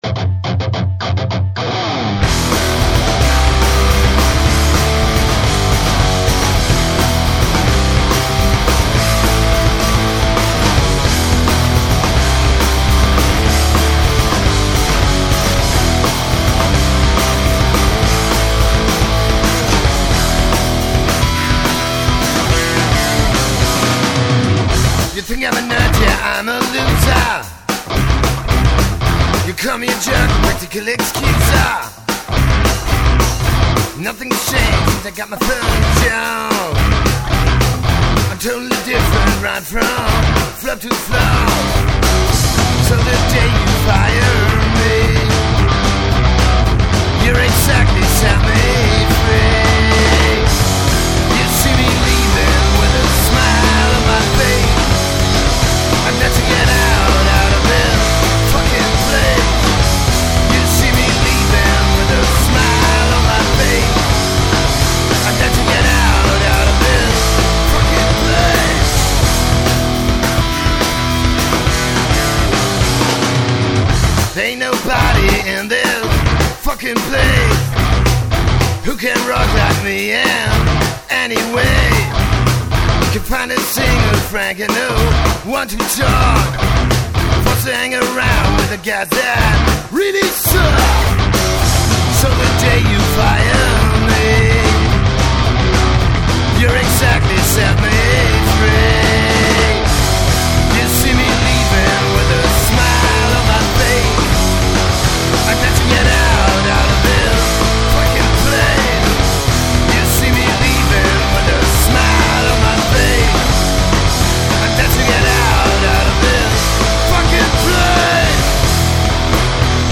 Demo Songs